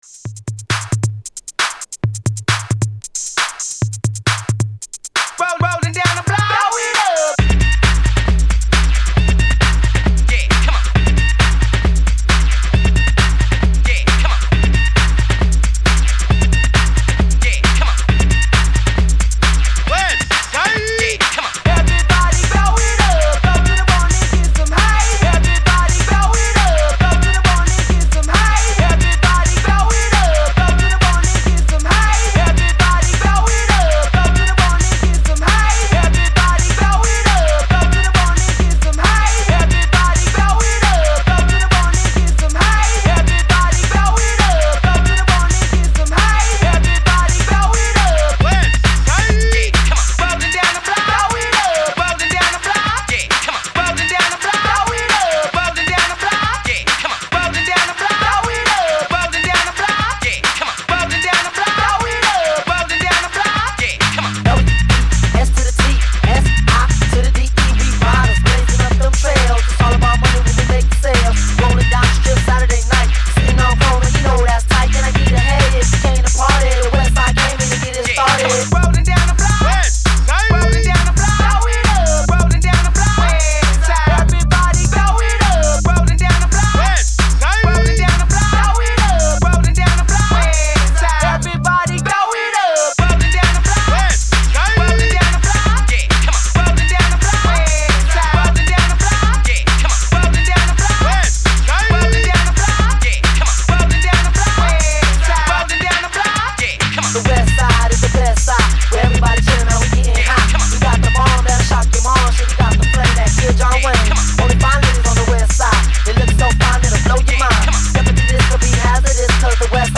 GHETTO HOUSE